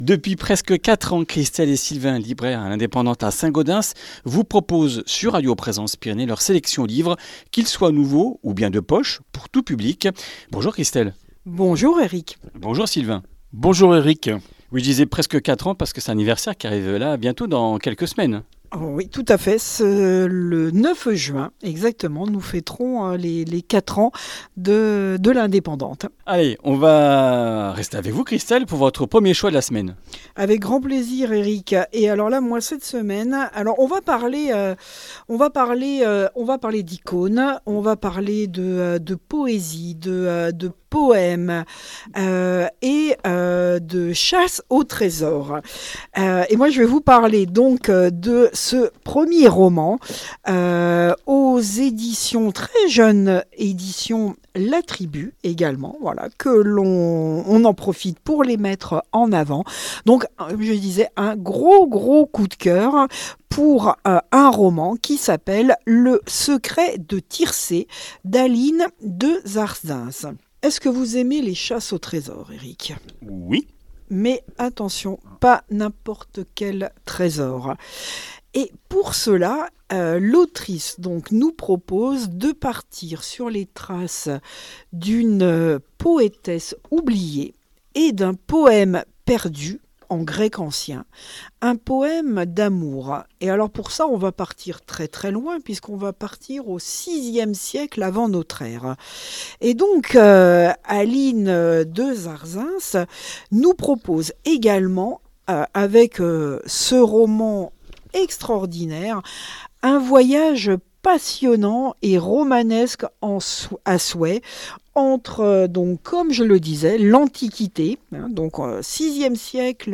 Comminges Interviews du 23 mai